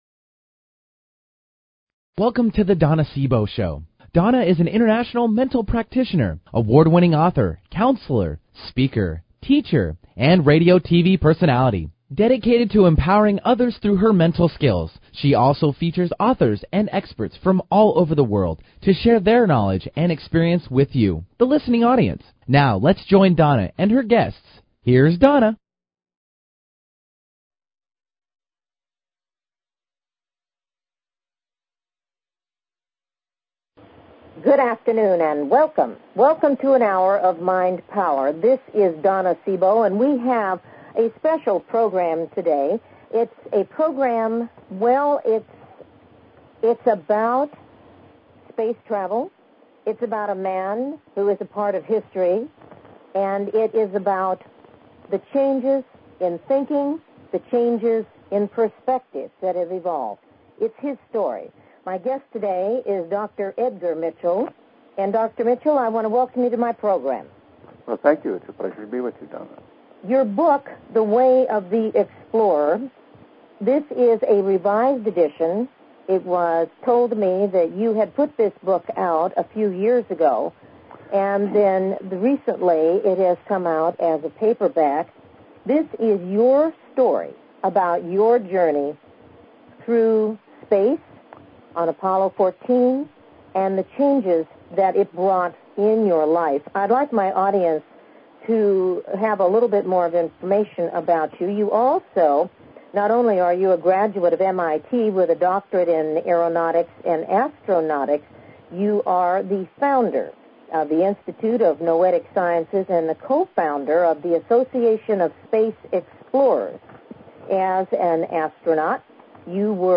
Talk Show Episode
Dr. Mitchell will be telling his story of exploration into outer space and how it transformed his thinking and his life. Don't miss this historical interview!!